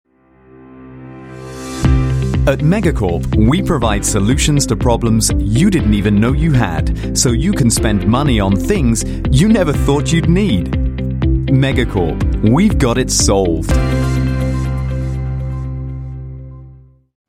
Voice Samples: Reel Sample 04
male
EN UK